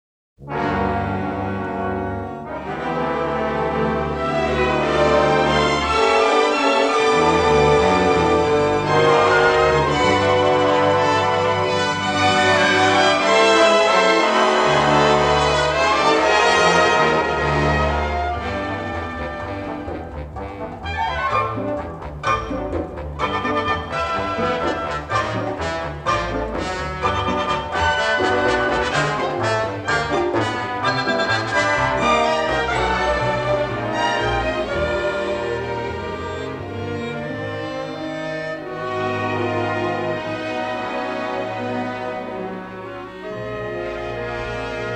a deeply melodic, romantic and sophisticated score